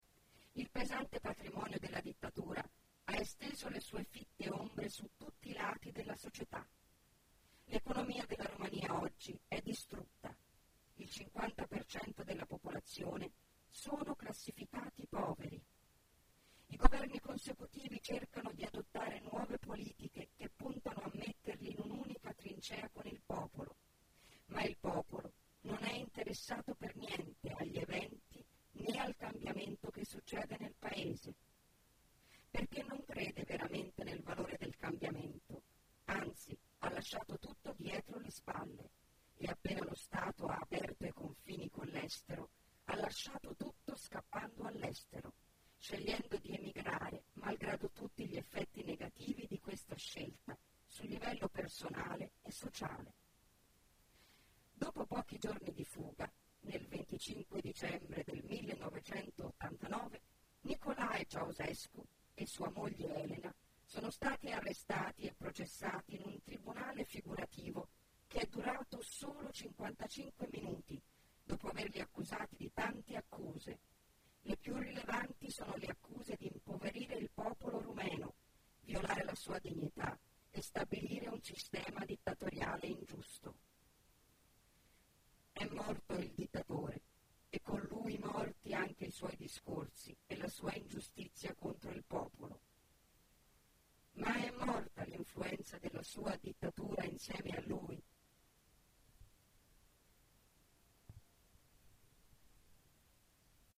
Kein Dialekt
Sprechprobe: Sonstiges (Muttersprache):